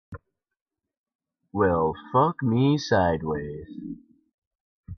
Tags: Freedom. DEEP. Nice